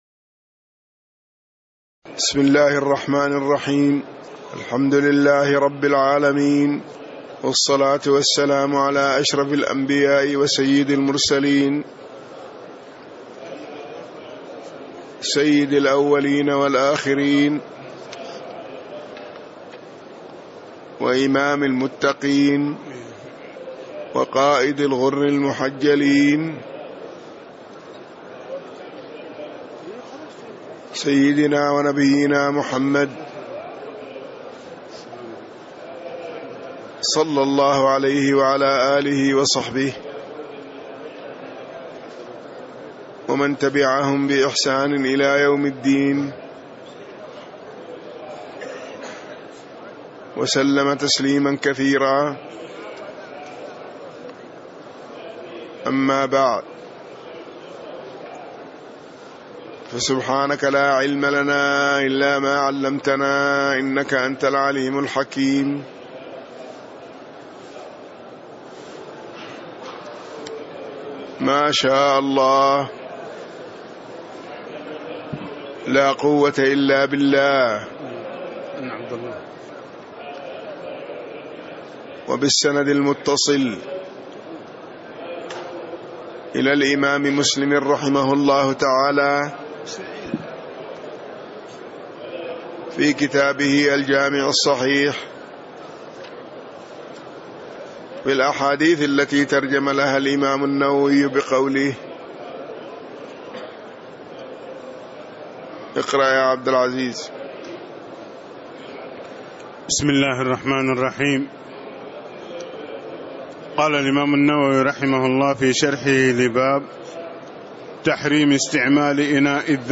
تاريخ النشر ١٢ رمضان ١٤٣٦ هـ المكان: المسجد النبوي الشيخ